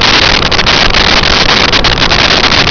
Sfx Amb Conveyor Loop
sfx_amb_conveyor_loop.wav